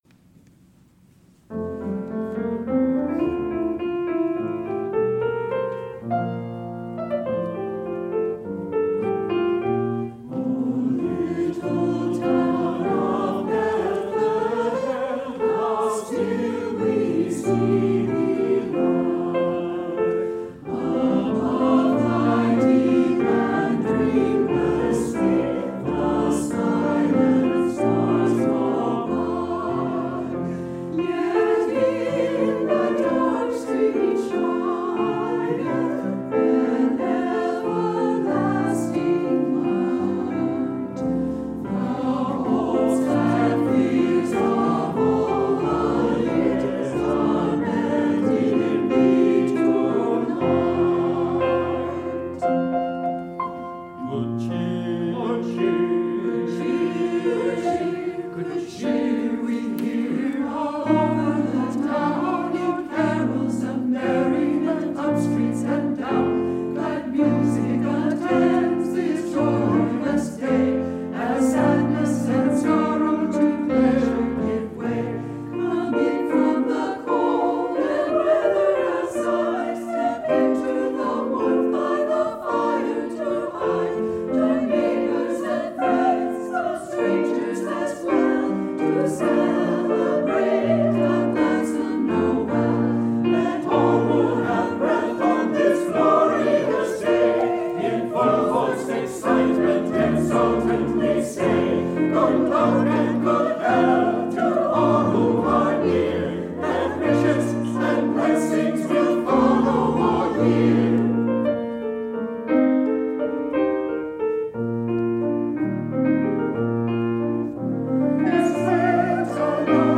Past QUUF Choir Recordings